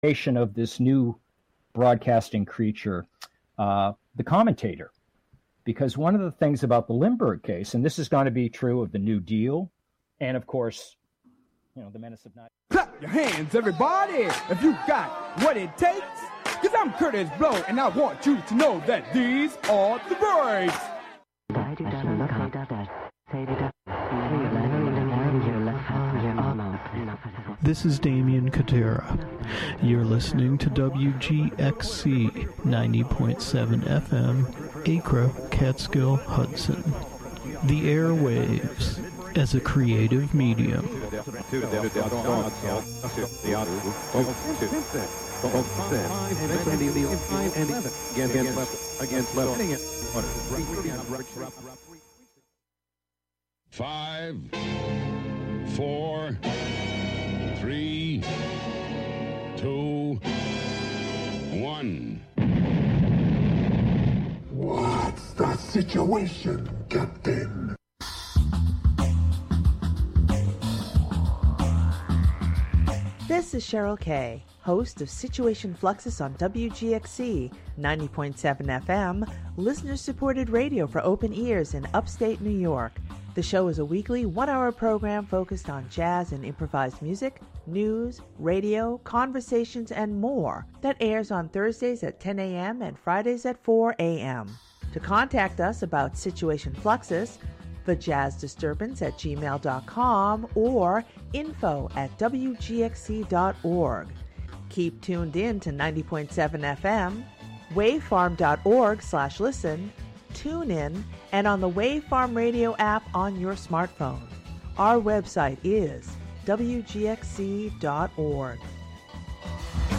Saturdays the show features weekly episodes of the "I Have Seen Niagara" serial.